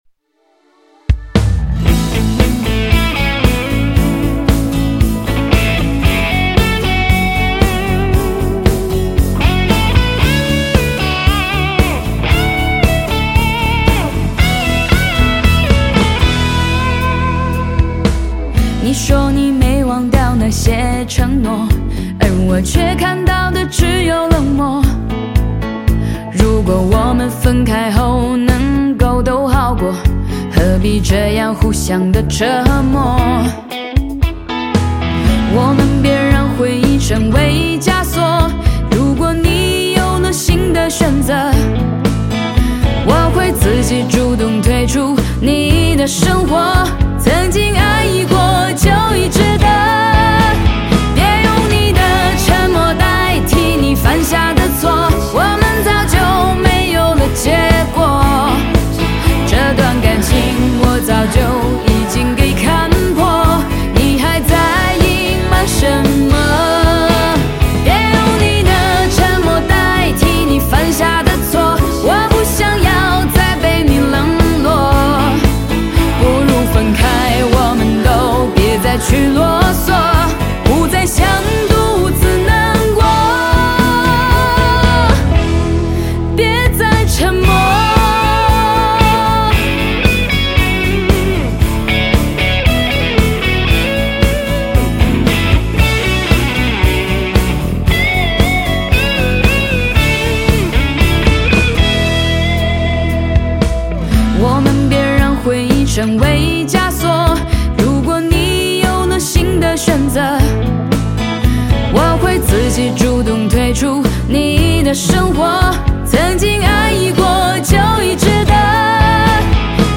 吉他Guitar
和声Backing Vocalist